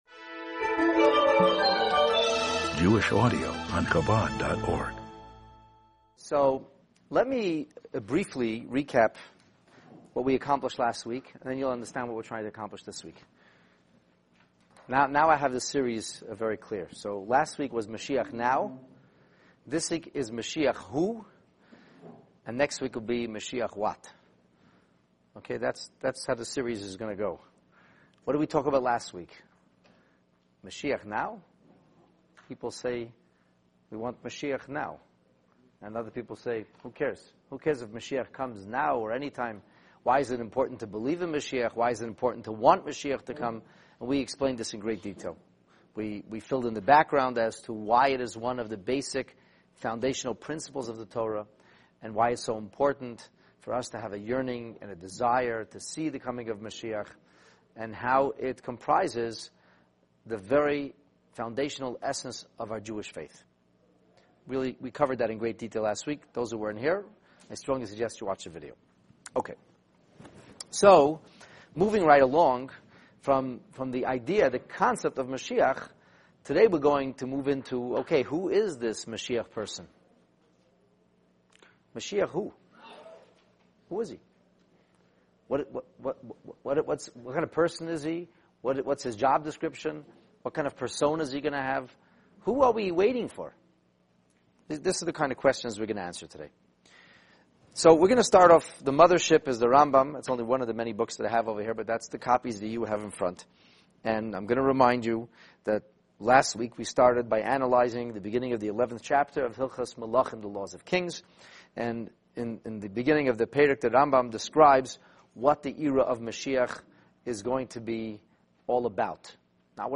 This class will enable you to positively ID the elusive “M” man!